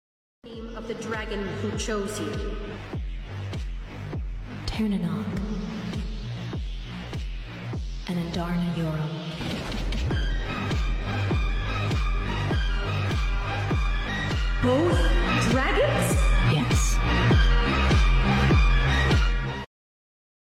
*cough cough* …2 days. 2 sound effects free download